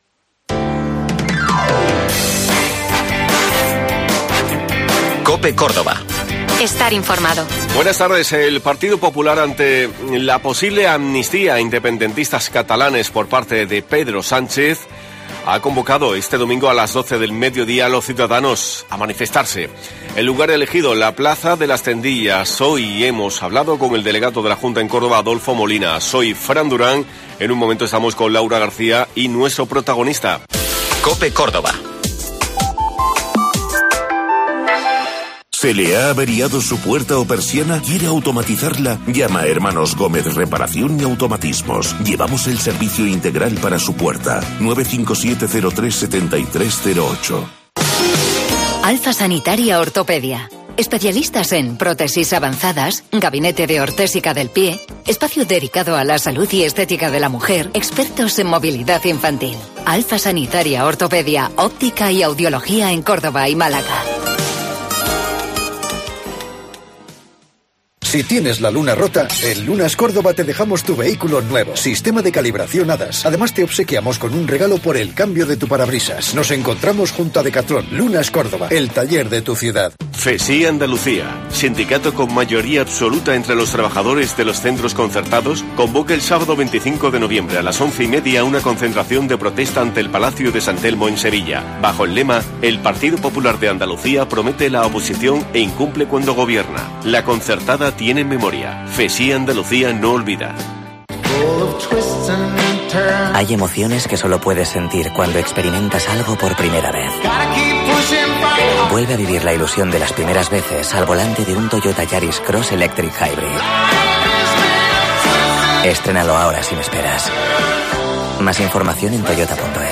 El domingo 12 de noviembre el Partido Popular de Córdoba hace un llamamiento para que se concentren en la plaza de las Tendillas para protestar por la posible Ley de Amnistía que prepara Pedro Sánchez a favor de los independentistas catalanes. Hemos hablado con el delegado de la Junta en Córdoba, Adolfo Molina.